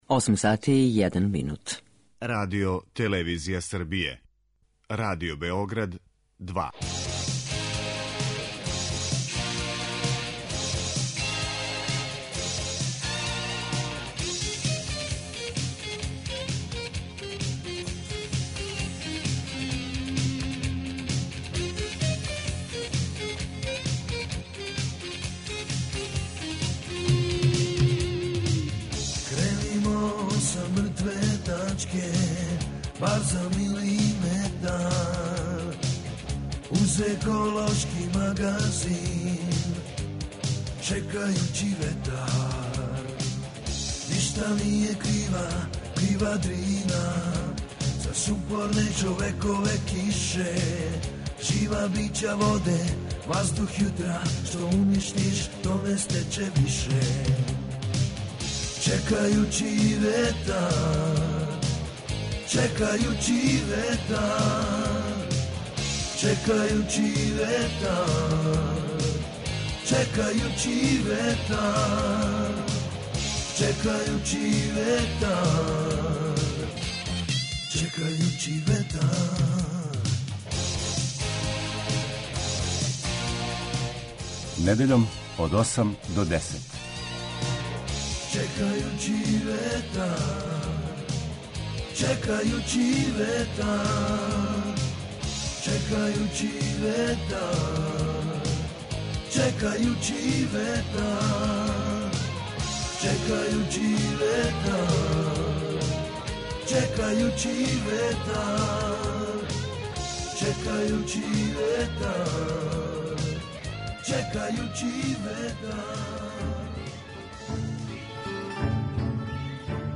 Новогодишња емисијa о даривању